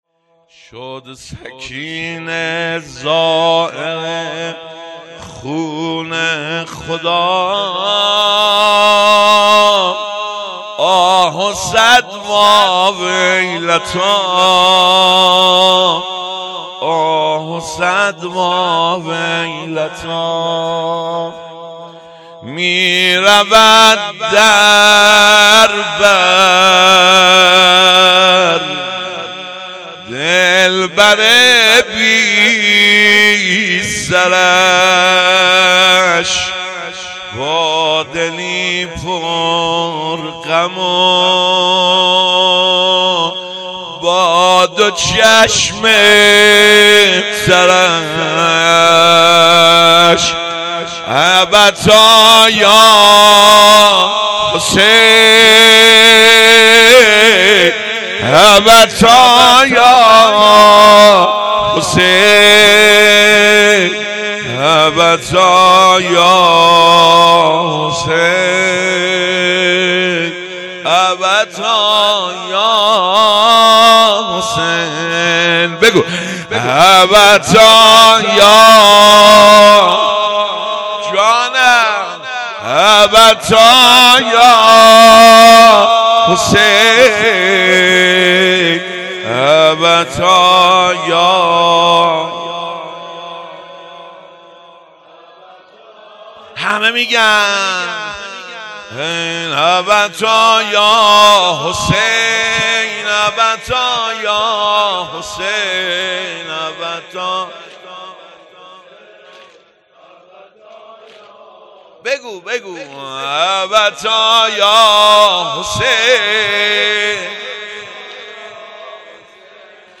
شب سوم شهادت حضرت محسن ابن علی علیه السلام ۱۴۰۲